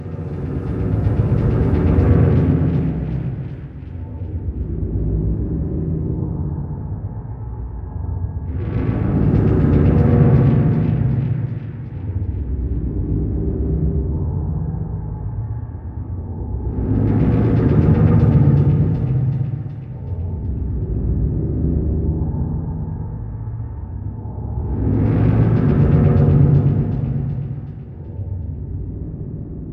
Lurking Underwater Creature
Tags: SOUND FX